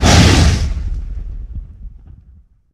punch1.ogg